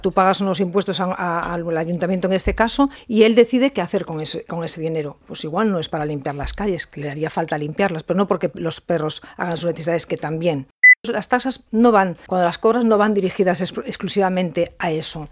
A l’entrevista política de Ràdio Calella TV també ha celebrat que s’hagi incrementat la inversió per millorar l’enllumenat públic i, de retruc, reforçar la seguretat, que ha estat una de les seves principals reivindicacions.